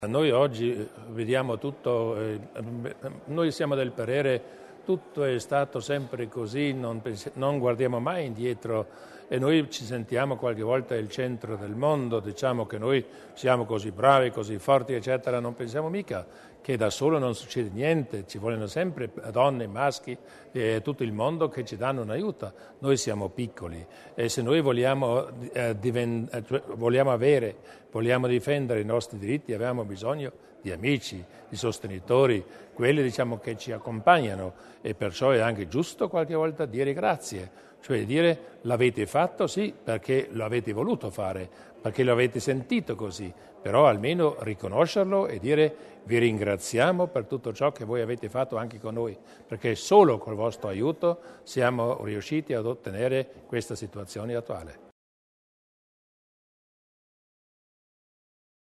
Il Presidente Durnwalder sul valore dell'Ordine
Così il presidente della Giunta provinciale Luis Durnwalder ha salutato le 8 personalità non residenti in Alto Adige che hanno ricevuto questa mattina (5 settembre) a Castel Tirolo l'Ordine di merito.